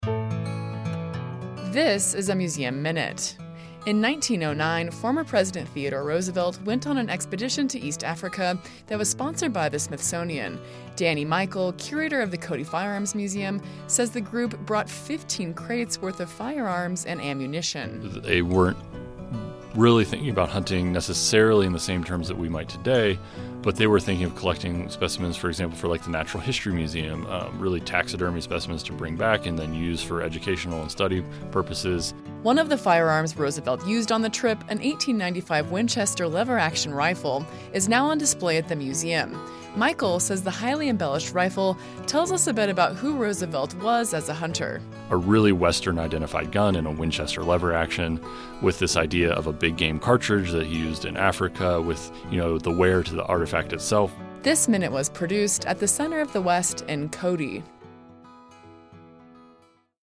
A 1-minute audio snapshot highlighting a museum object from the collection of the Buffalo Bill Center of the West.